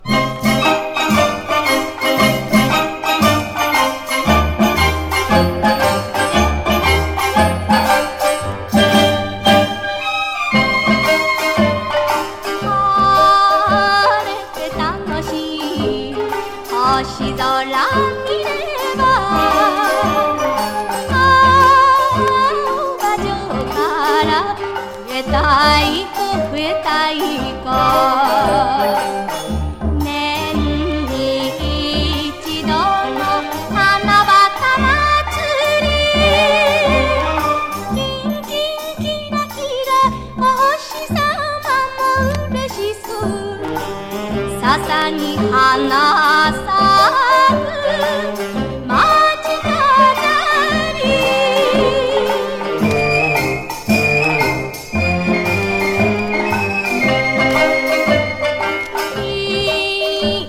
エキゾチック歌声堪能輪踊りキンキンキラキラお星さまも嬉しそう～